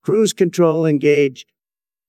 cruise-control-engaged.wav